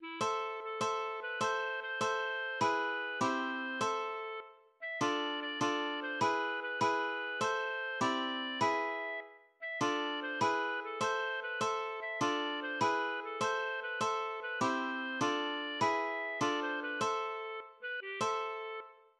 } myMusic = { << \chords { \germanChords \set chordChanges=##t \set Staff.midiInstrument="acoustic guitar (nylon)" s8 | a4.:m a4.:m | a4.:m a4.:m | e4. c4.
| a4.:m s4 } \relative c' { \key a \minor \time 6/8 \tempo 4.=100 \set Staff.midiInstrument="clarinet" \partial 8 e8 | a4 a8 a4 b8 | c4 c8 c4.